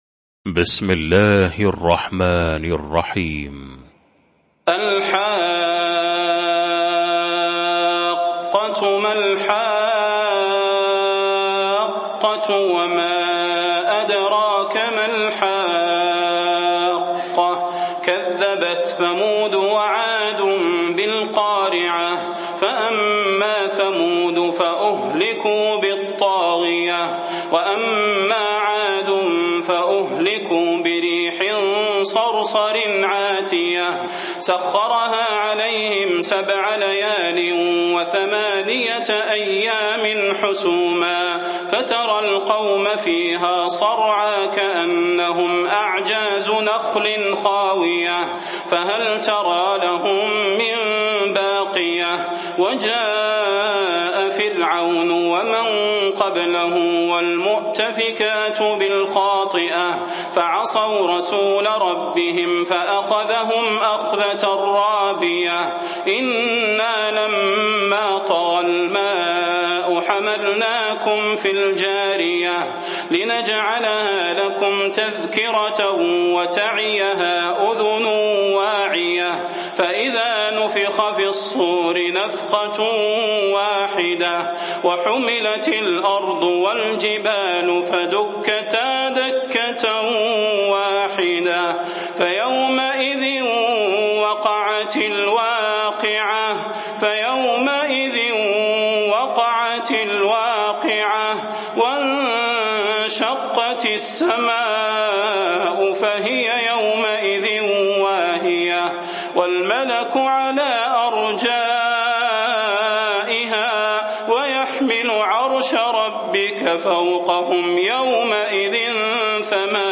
المكان: المسجد النبوي الشيخ: فضيلة الشيخ د. صلاح بن محمد البدير فضيلة الشيخ د. صلاح بن محمد البدير الحاقة